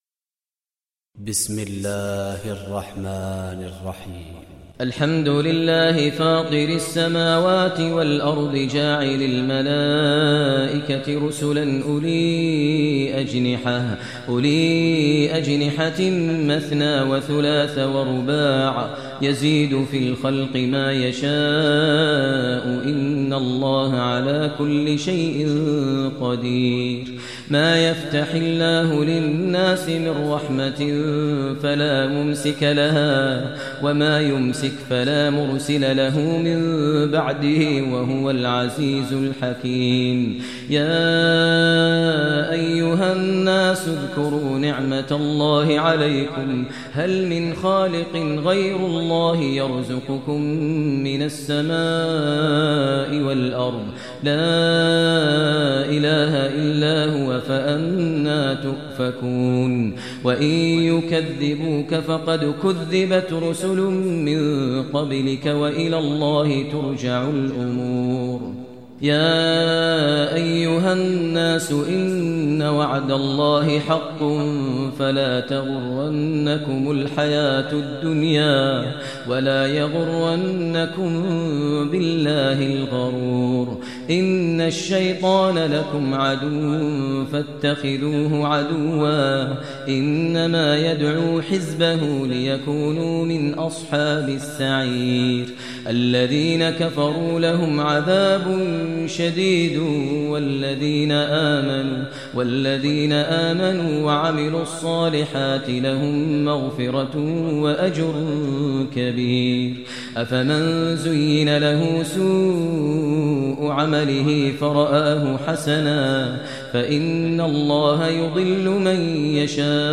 Surah Fatir Recitation by Maher al Mueaqly
Surah Fatir is 35 chapter of Holy Quran. Listen online mp3 tilawat / recitation in the voice of Sheikh Maher al Mueaqly.